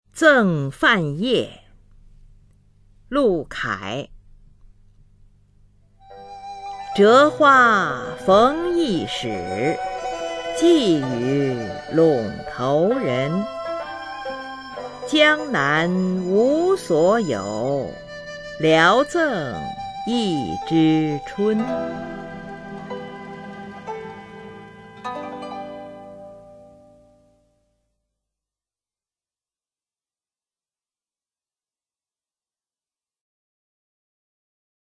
[魏晋诗词诵读]陆凯-赠范晔 古诗朗诵